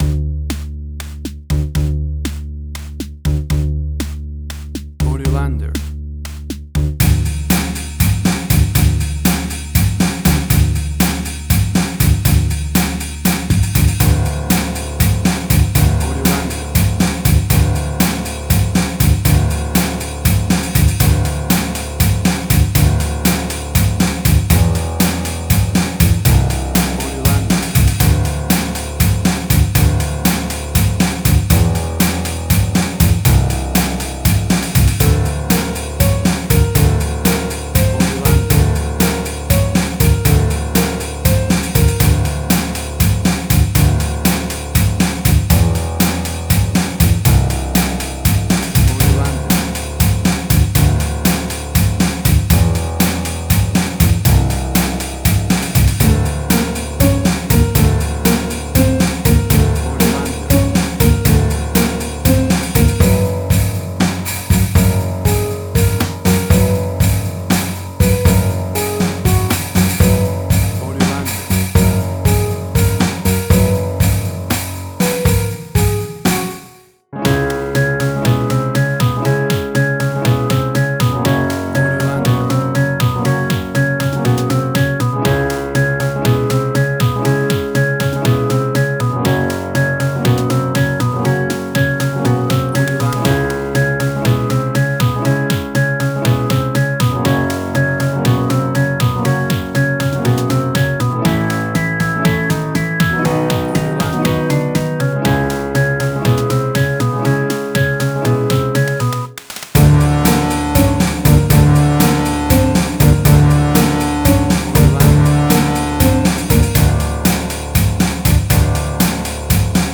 Alternative Songs with vocals.
Tempo (BPM): 126